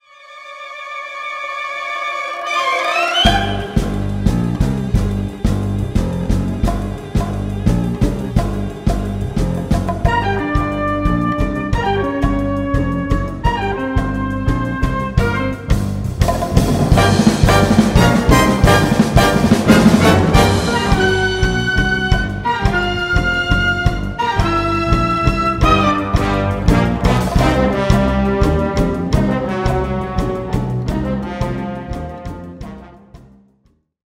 Ce rythme crée une impression de tension et d’urgence.